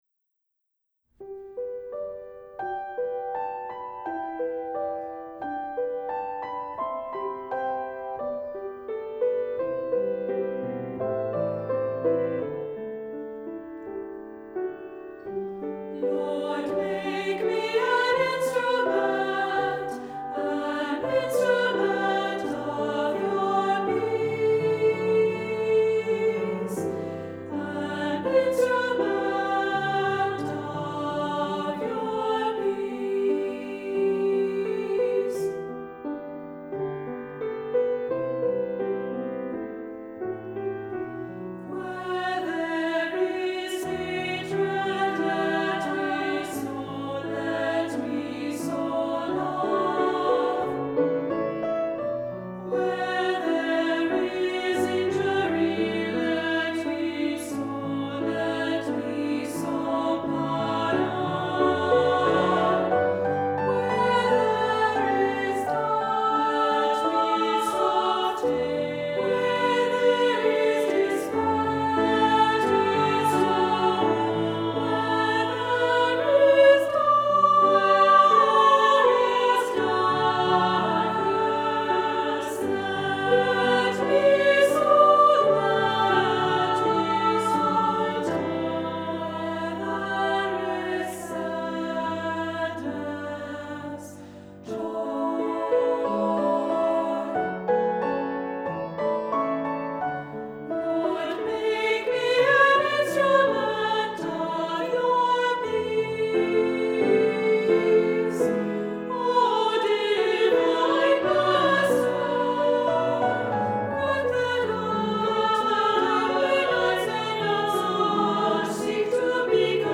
Voicing: Two-part equal